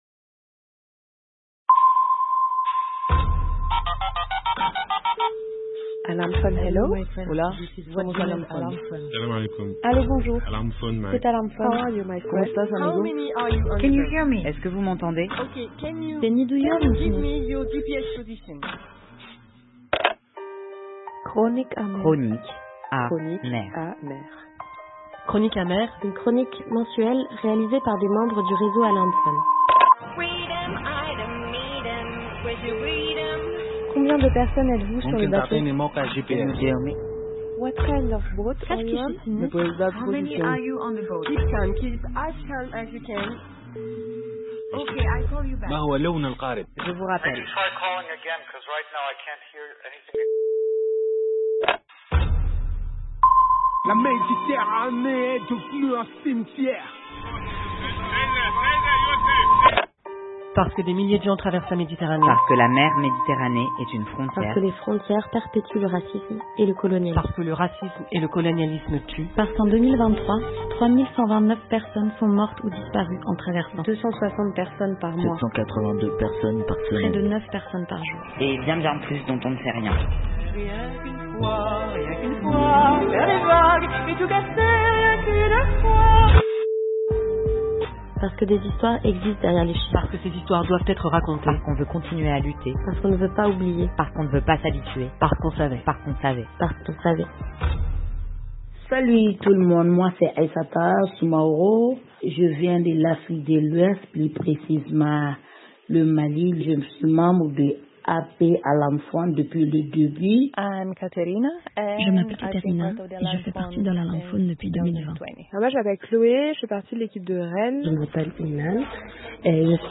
On a profité de ce moment pour enregistrer nos camarades en leur demandant de partager des histoires qui le